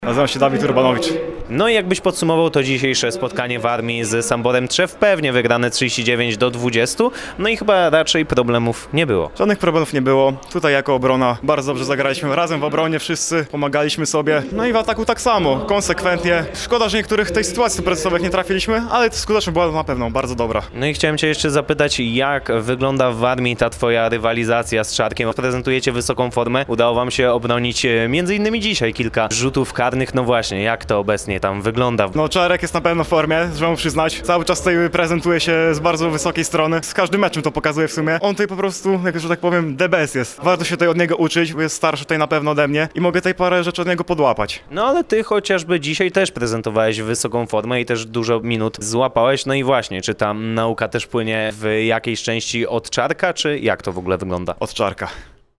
Posłuchajcie, jak młody bramkarz ocenił swój występ!